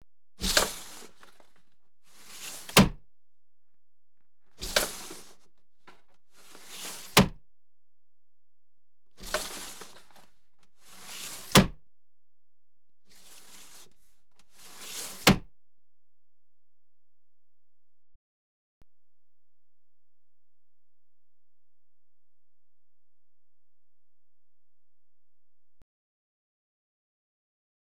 Window Open Close Sound Effect
Download a high-quality window open close sound effect.
window-open-close.wav